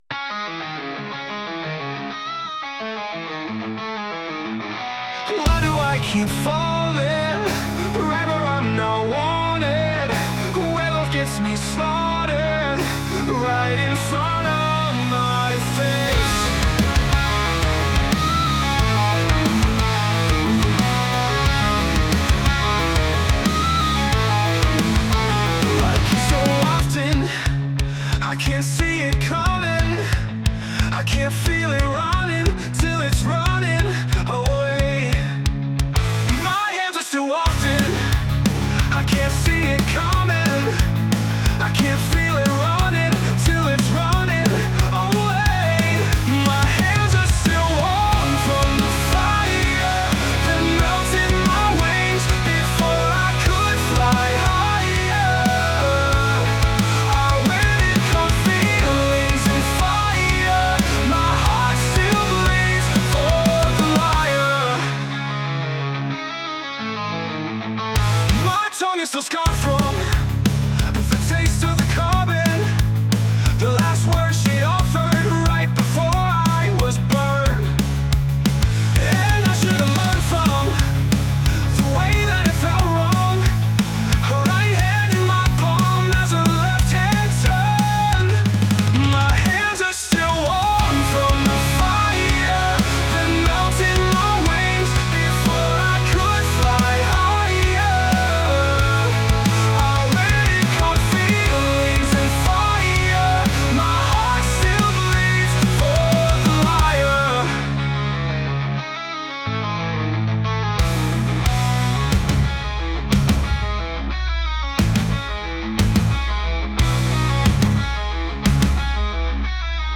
Genre: Alternative Metal/Metalcore/Deathcore